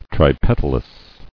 [tri·pet·al·ous]